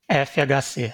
Fernando Henrique Cardoso GCB GCTE GCoIISE GColIH GColL GCM RE DMN CYC OMRI (Brazilian Portuguese: [feʁˈnɐ̃du ẽˈʁiki kaʁˈdozu] ; born 18 June 1931), also known by his initials FHC (Brazilian Portuguese: [ˌɛfjaɡaˈse]